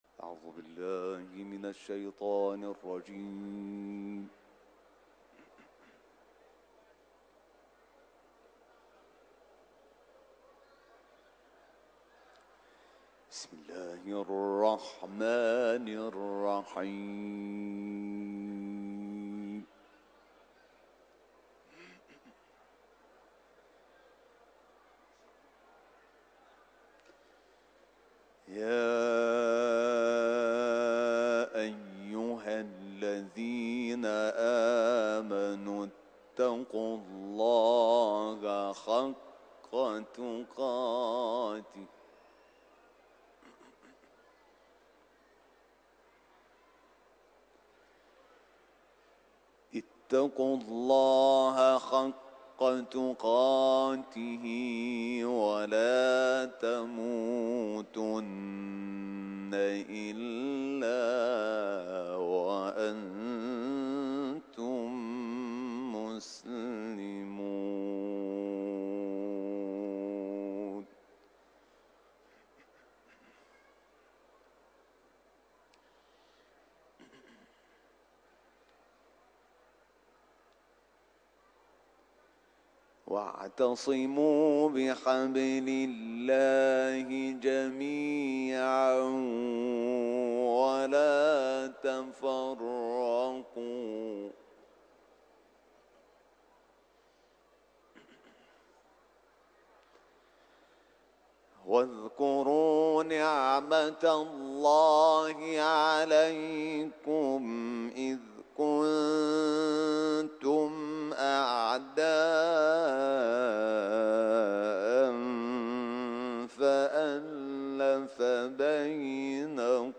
صوت | تلاوت حمید شاکرنژاد از سوره «آل عمران»
صوت تلاوت آیات ۱۰۲ تا ۱۰۸ از سوره «آل عمران» با صدای حمید شاکرنژاد، قاری بین‌المللی قرآن که در حرم رضوی اجرا شده است، تقدیم مخاطبان ایکنا می‌شود.
تلاوت